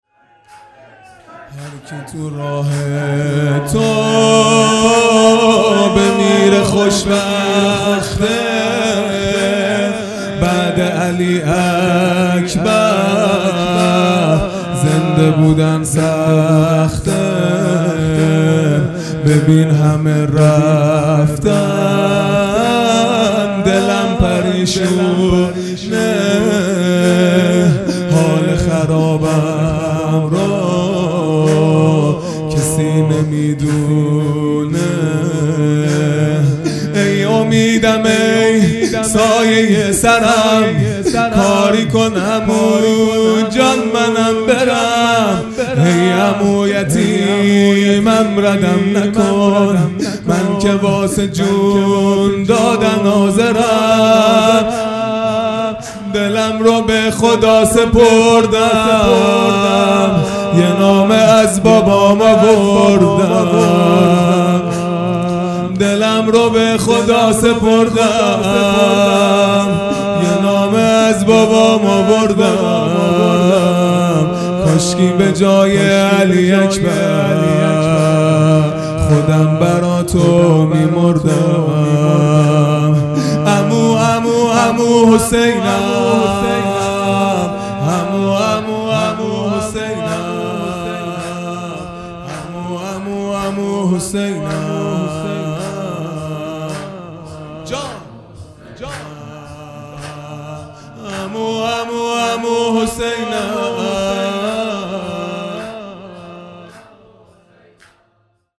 خیمه گاه - هیئت بچه های فاطمه (س) - زمینه | هرکی تو راه تو بمیره خوشبخته
دهه اول محرم الحرام ۱۴۴٢ | شب ششم